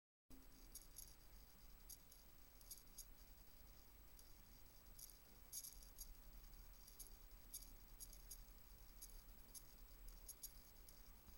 Raspi macht Geräusche (ohne Lüfter)
Mein Raspi 3 macht Geräusche und das ohne Lüfter, passiv gekühlt. Sehr leise, aber wenn man das Ohr dicht an den Raspi hält, dann hört man das.